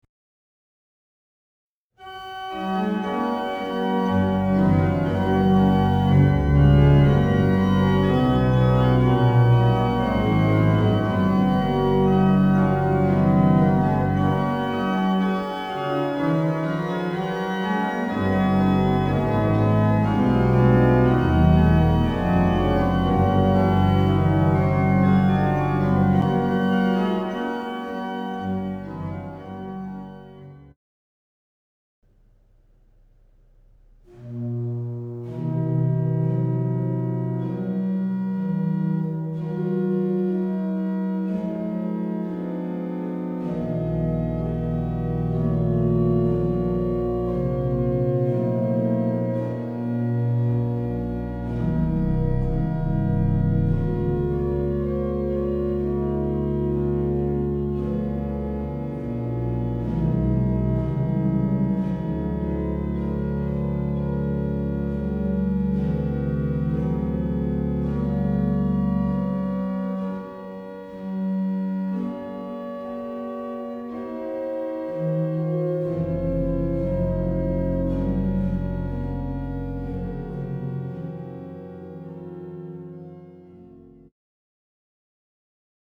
Kullamaan kirkon hyvin säilyneet, Carl Tantonin vuonna 1854 rakentamat urut ovat epäilemättä jääneet soimaan Tobiasin urkuteoksissa. Hugo Lepnurm soittaa levyllä Eesti orelid 8 (vuodelta 1975) Tobiasin urkukoraalit Ach, was soll ich Sünder machen, O du Liebe meiner Liebe, Nun freut euch ja Sollt' ich meinem Gott nicht singen.